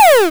Sound Effects
WaveProjectile.wav